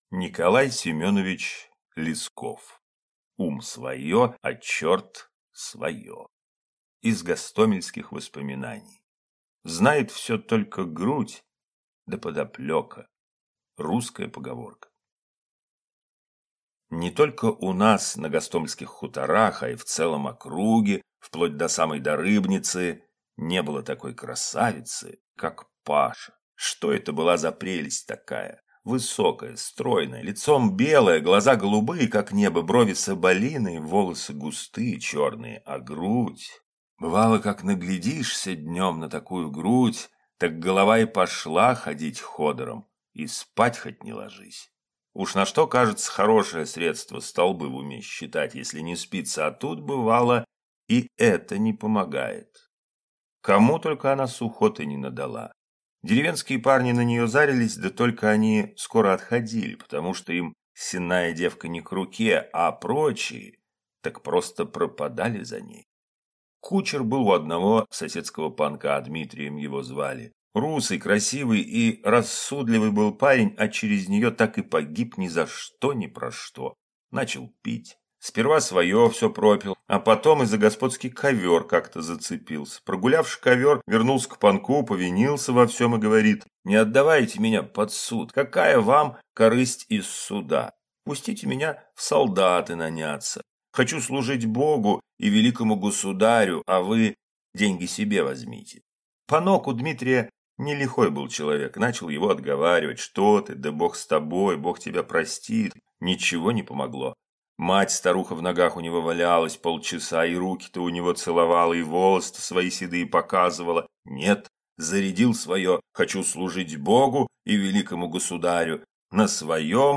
Аудиокнига Ум свое, а черт свое | Библиотека аудиокниг